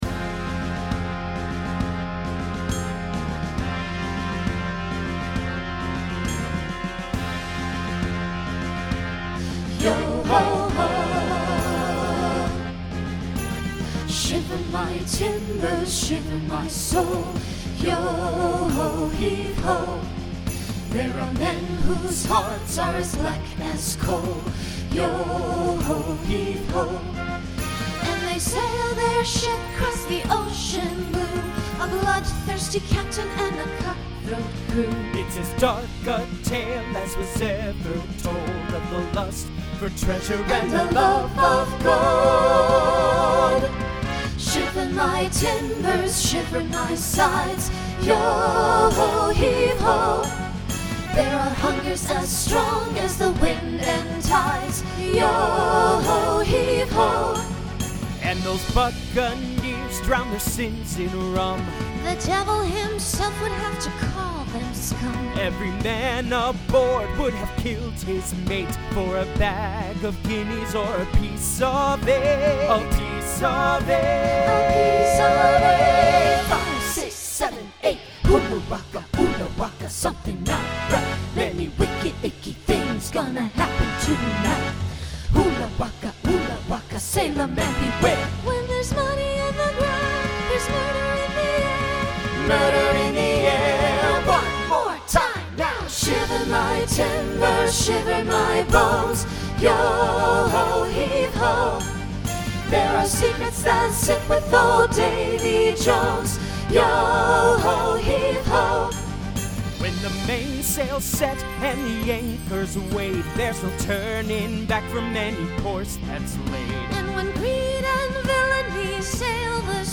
New SSA voicing for 2026.
Genre Broadway/Film Instrumental combo
Story/Theme Voicing SATB , SSA